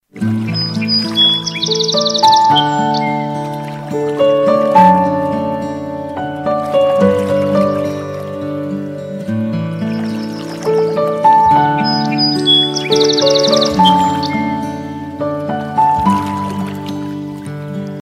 알림음 8_자연의벨소리.ogg